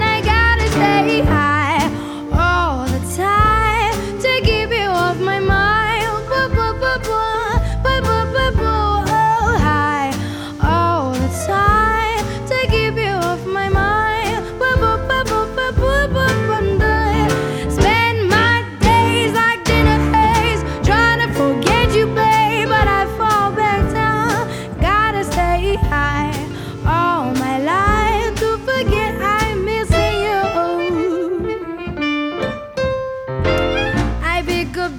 Жанр: Поп
# Jazz